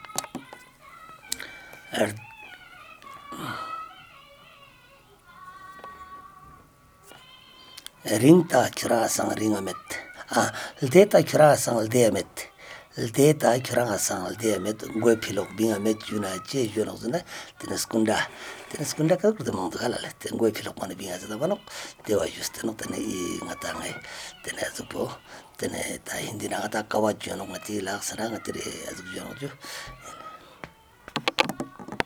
Retelling of a story in Beda - Part 9